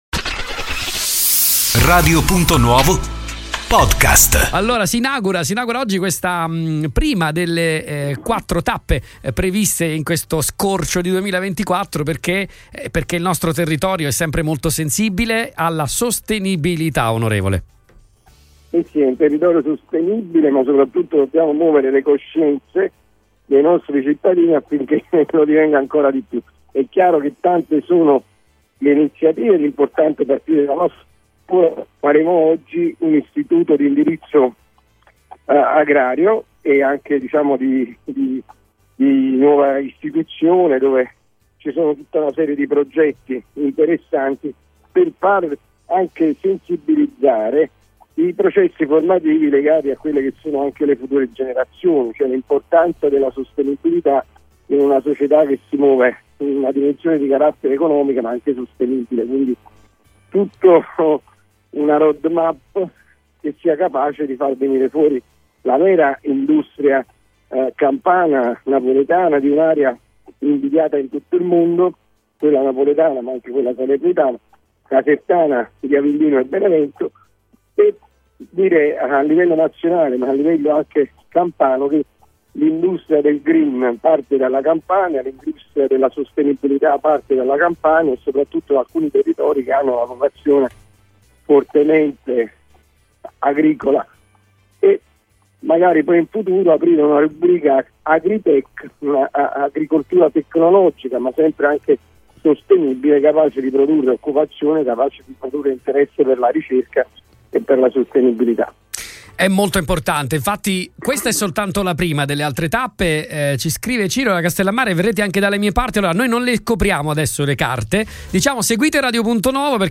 Ai nostri microfoni questa mattina l’onorevole Francesco Iovino, presidente dell’Osservatorio AgroGreen della Regione Campania, che ha evidenziato l’importanza di partire dalla formazione per costruire una società capace di coniugare sviluppo economico e rispetto per l’ambiente.